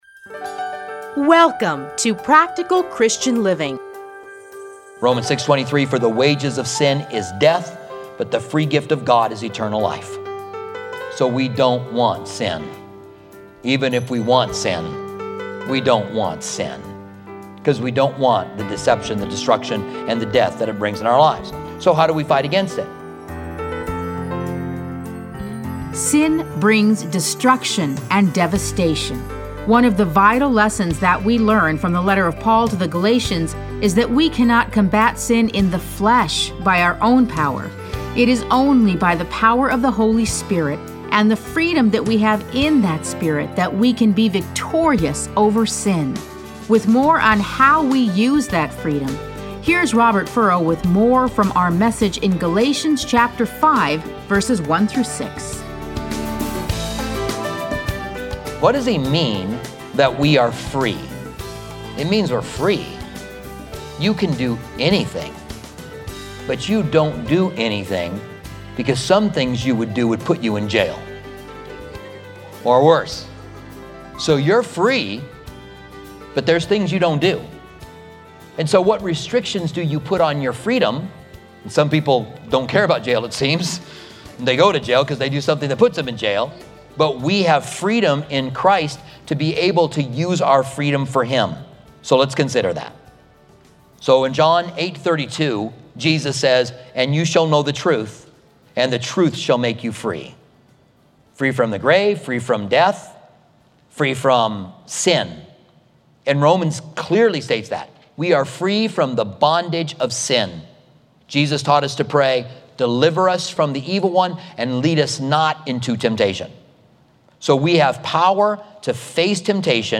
Listen to a teaching from Galatians 5:1-6.